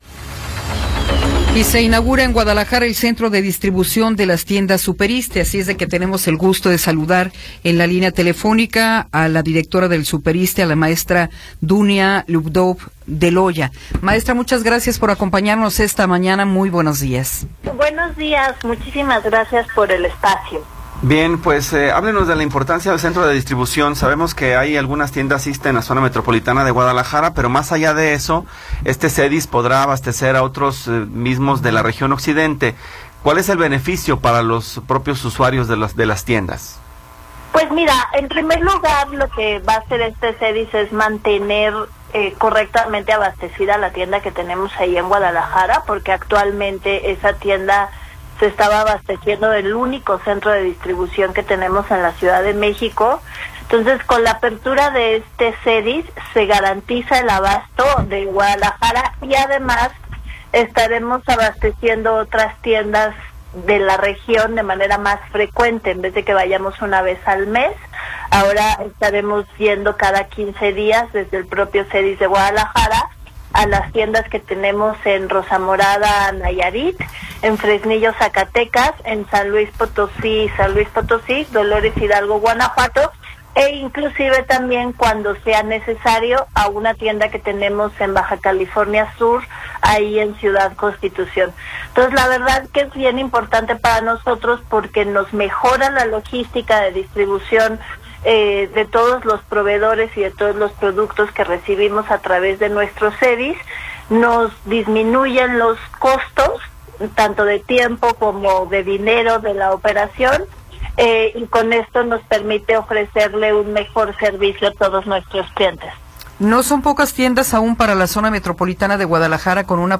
Entrevista con Dunia Ludlow Deloya